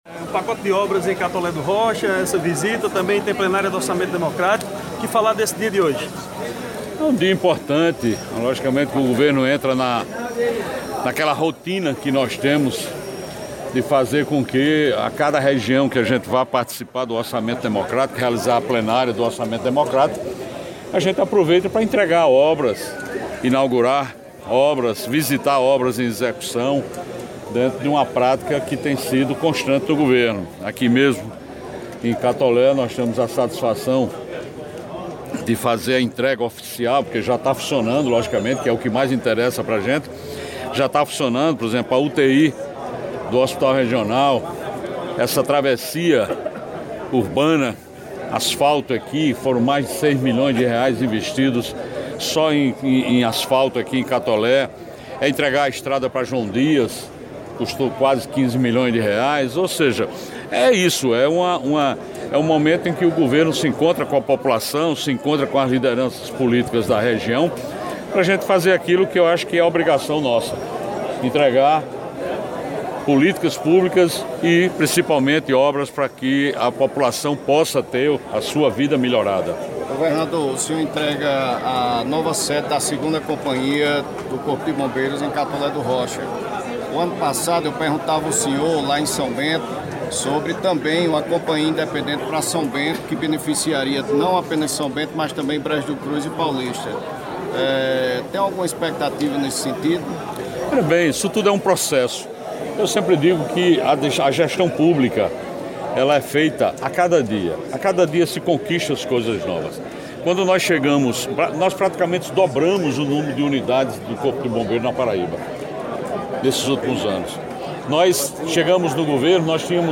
Ouça na íntegra a coletiva de imprensa do Governador João Azevêdo em Catolé do Rocha - Folha Paraibana
Na sede da 2ª Companhia Independente do Corpo de Bombeiros Militar, o Governador João Azevêdo concedeu entrevista a imprensa local, ouça na íntegra:
Coletiva-de-Imprensa-com-o-Governador-Joao-Azevedo.mp3